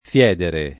fiedere [ f L$ dere ]